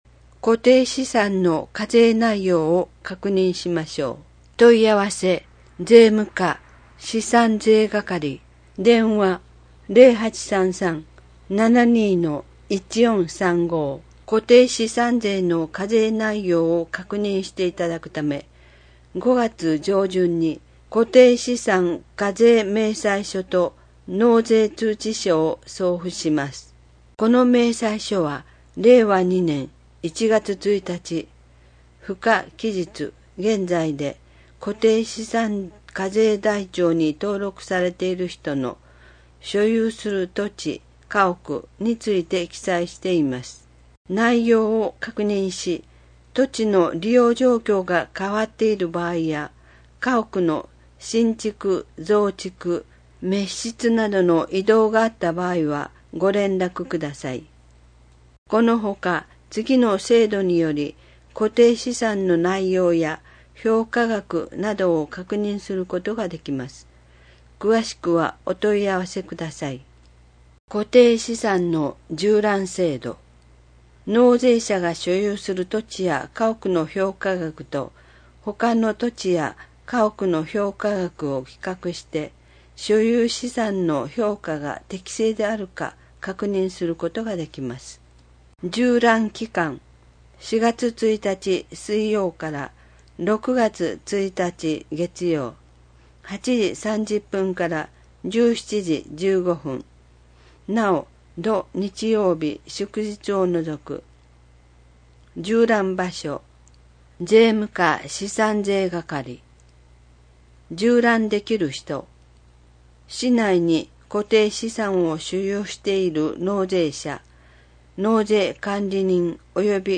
広報ひかりでは、 ボランティアグループ「こだまの会」の協力により文字を読むことが困難な視覚障害者や高齢者のために広報紙の内容を音声でもお届けしています。